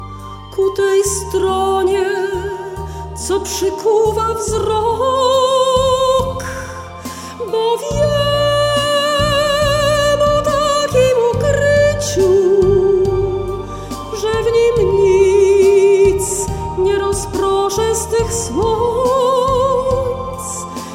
pieśniarka, instruktorka muzyki